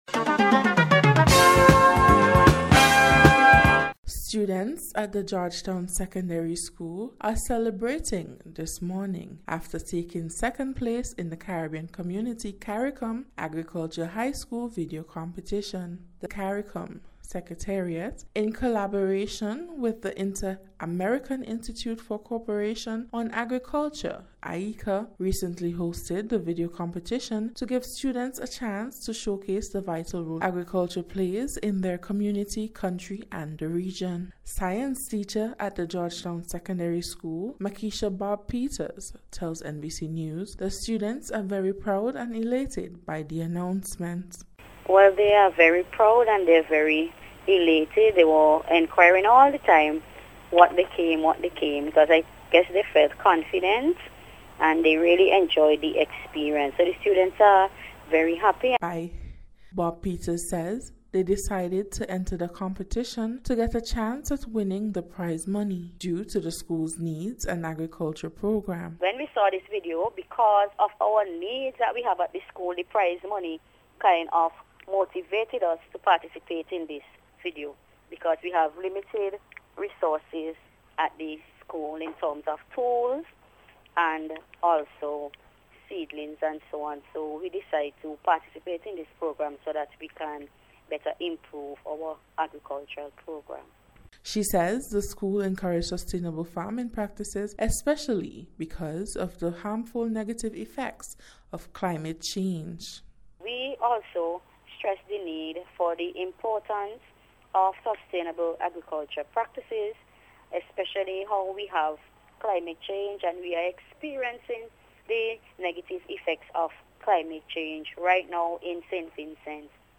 NBC’s Special Report- Wednesday 15th January,2025